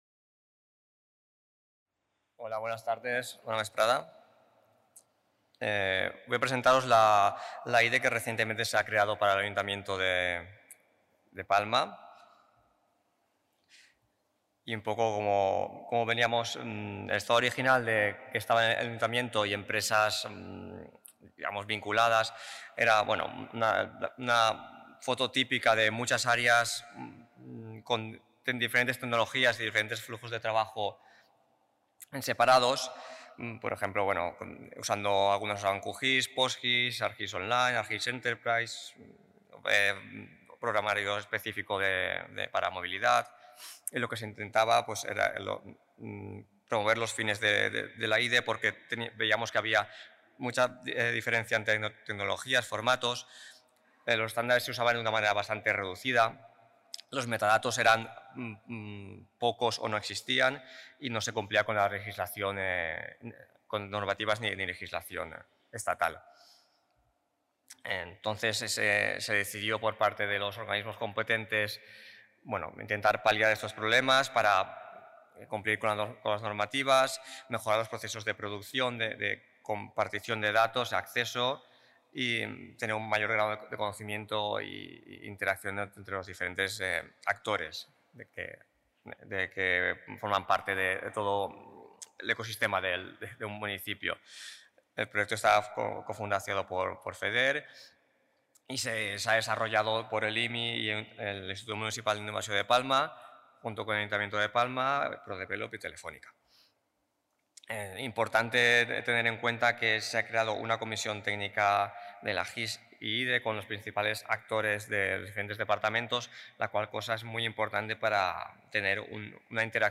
Xerrada sobre la creació d'una infraestructura de Dades Espacials (IDE) per part de l'Ajuntament de Palma de Mallorca amb l'objectiu de millor la coordinació entre els diferents departaments de l'Ajuntament i per tant gestionar millor el municipì gràcies a la recollida de les dades i la visibilització en mapes